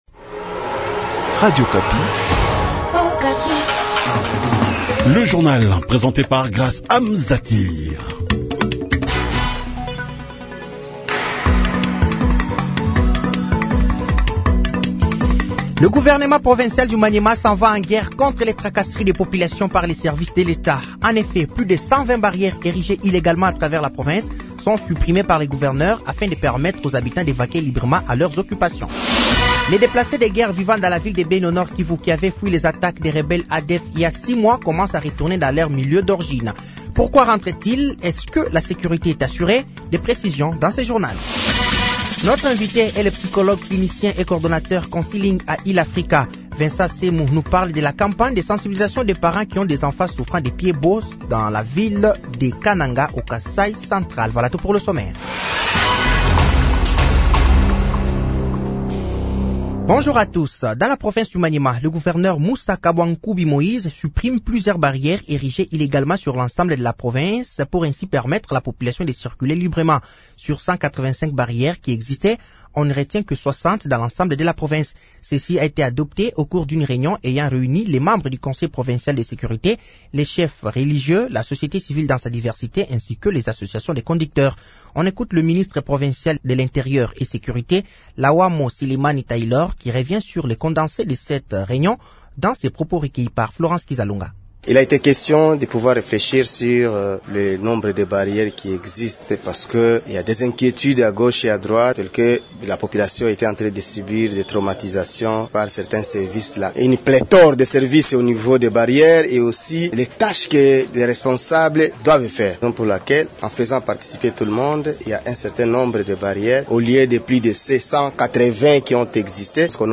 Journal français de 8h de ce jeudi 15 août 2024